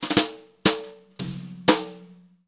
120JZFILL1-L.wav